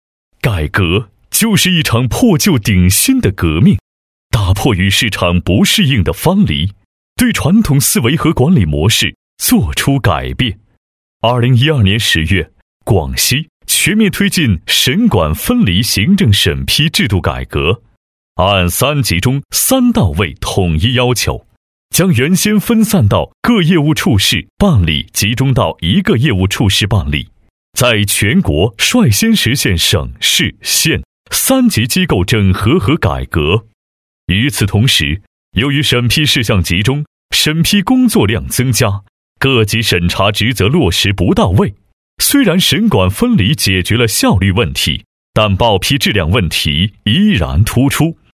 成熟稳重 企业专题,人物专题,医疗专题,学校专题,产品解说,警示教育,规划总结配音
优质男中音，声音特点，大气浑厚，沉稳厚实，时尚。